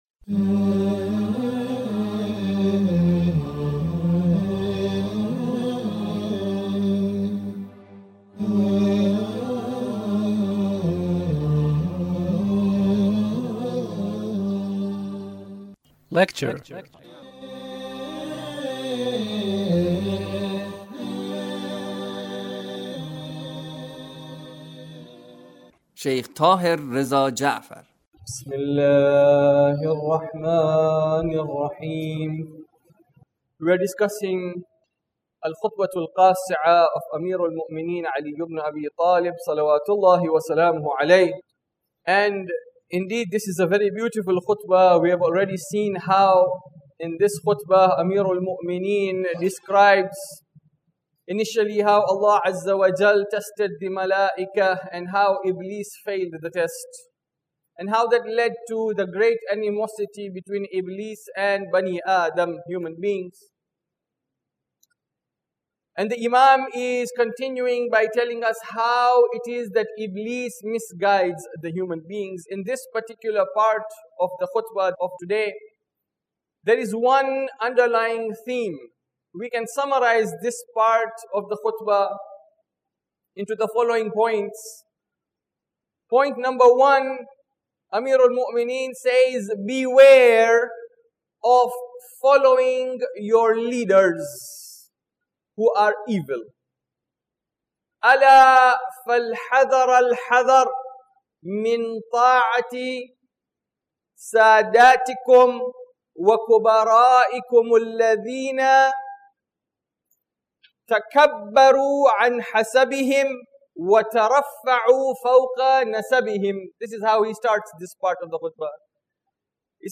Lecture (34)